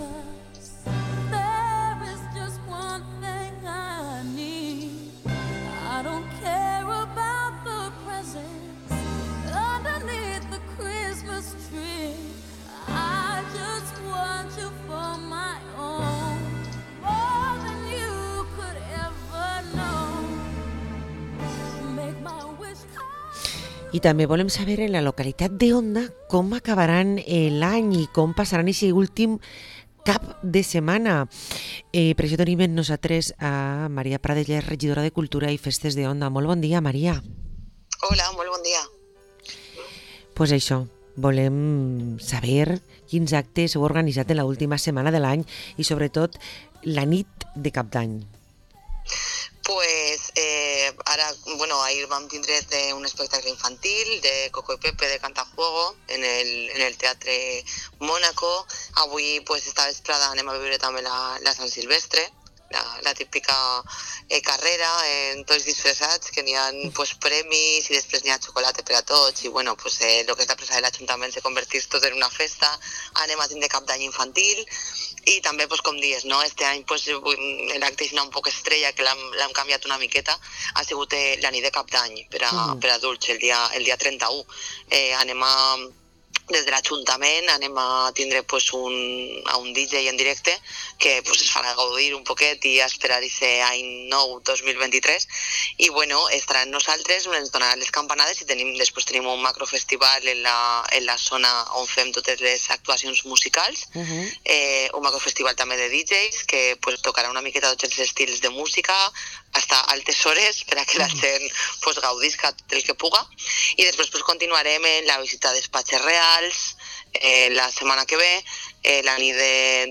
La regidora de Cultura i Festes d’Onda, Maria Prades, ens comenta els actes dels quals podrem gaudir estos últims dies de 2022 a la localitat, sobretot la Nit de Cap d’any, i ens fa un balanç de l’any